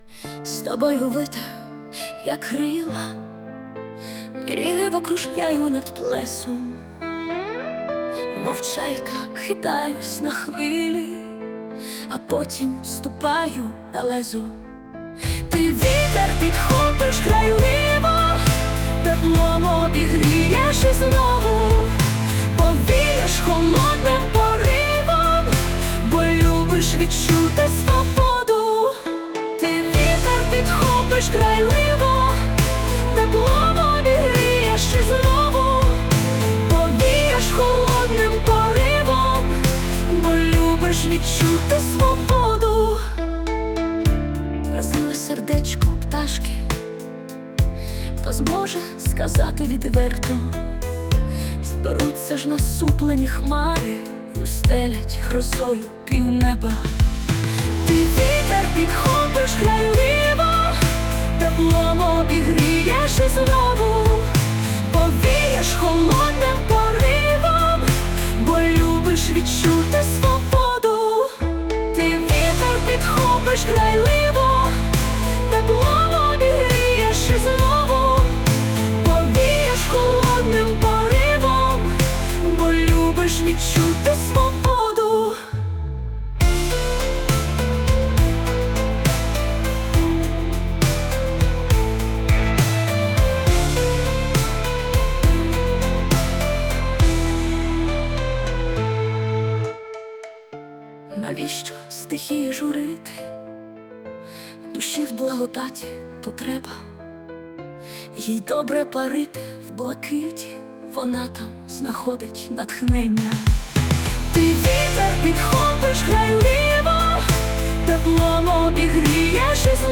Текст - автора, мелодія і виконання - ШІ
СТИЛЬОВІ ЖАНРИ: Ліричний